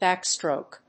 音節báck・stròke 発音記号・読み方
/ˈbæˌkstrok(米国英語), ˈbæˌkstrəʊk(英国英語)/